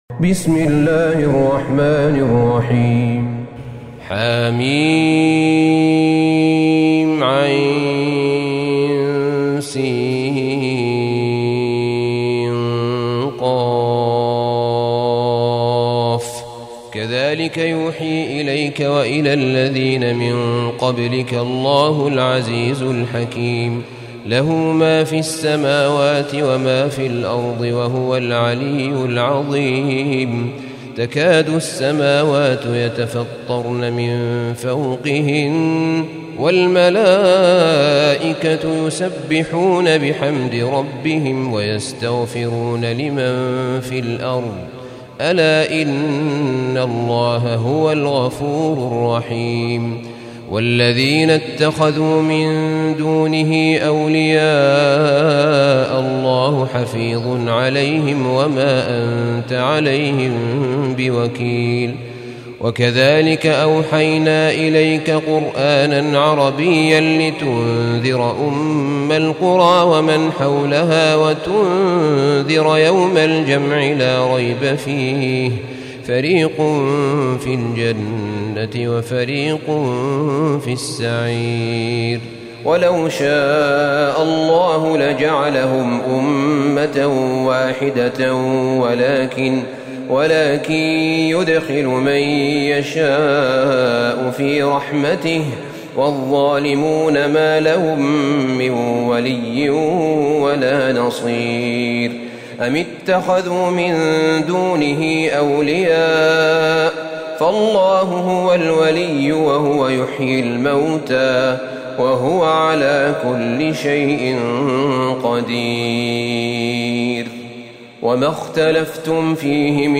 سورة الشورى Surat AshShura > مصحف الشيخ أحمد بن طالب بن حميد من الحرم النبوي > المصحف - تلاوات الحرمين